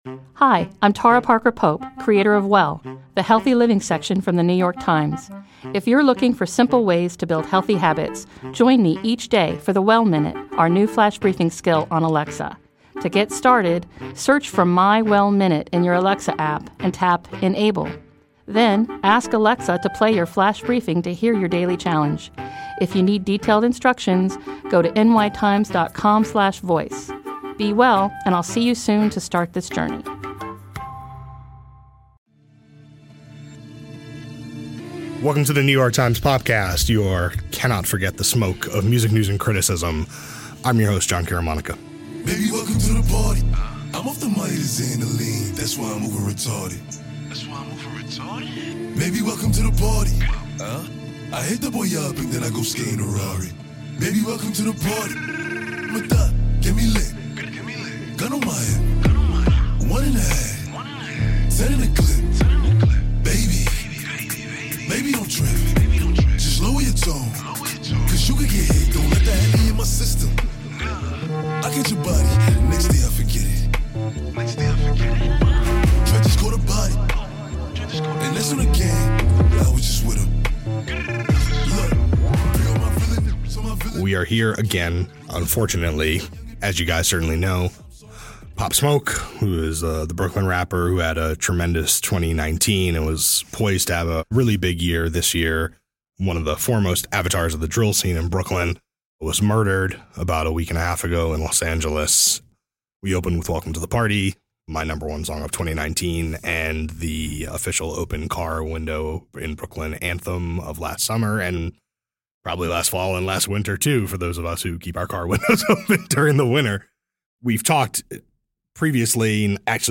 A conversation about Pop Smoke's career and the scene he was poised to bring to the world.